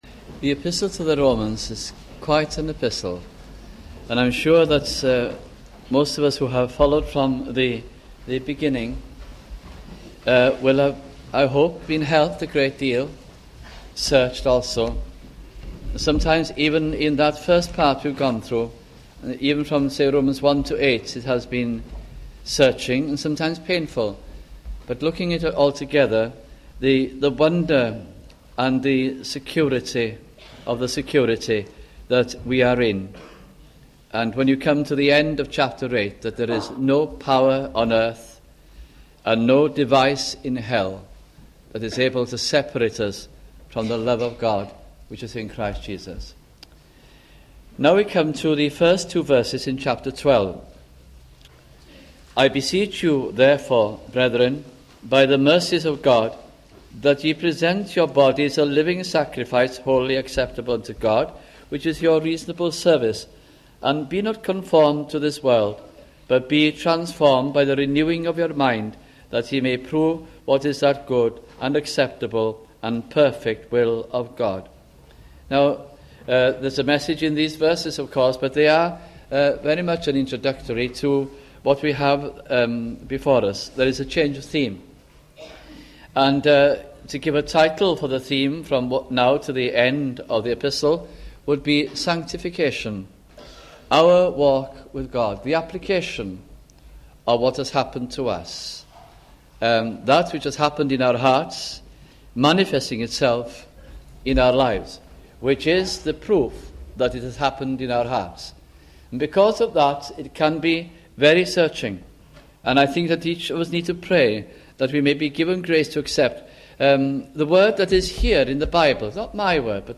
» Romans Series 1987 - 1988 » sunday morning messages